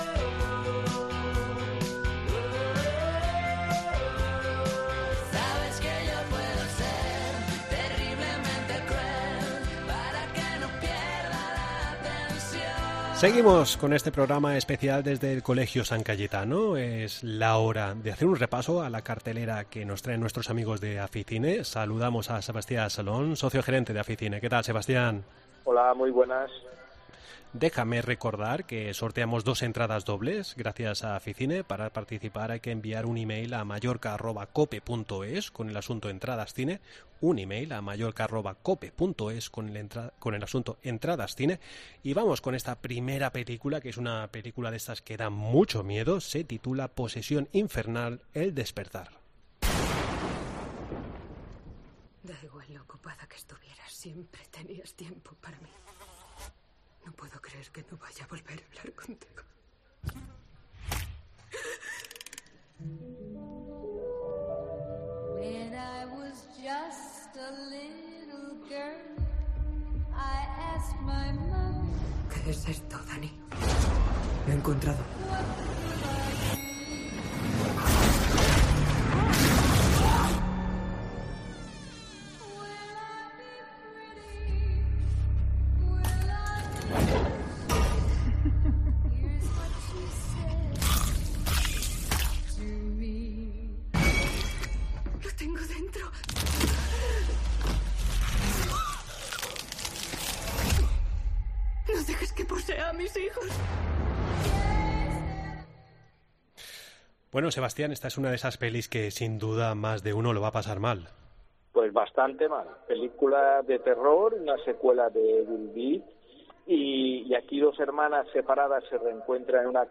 . Entrevista en La Mañana en COPE Más Mallorca, viernes 21 de abril de 2023.